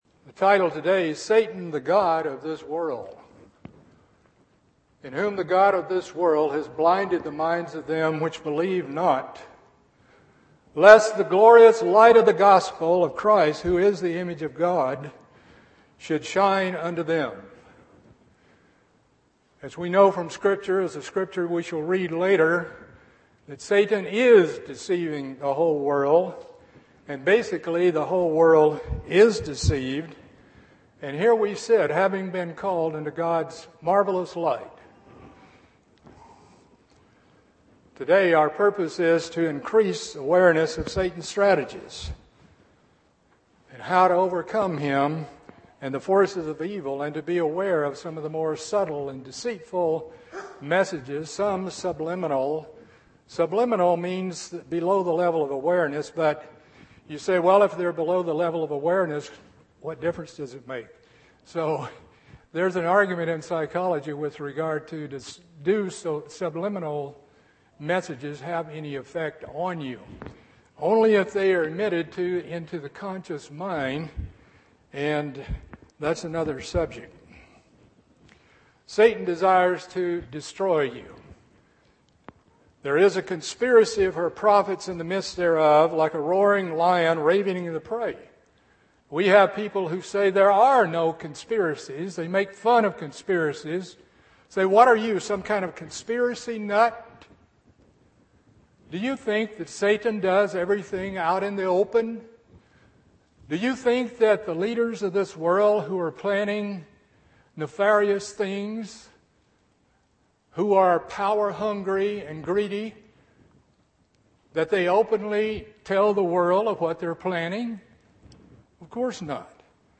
This sermon increases awareness of Satan’s strategies and how to overcome him and the forces of evil. We need to be aware of his more subtle and deceitful messages.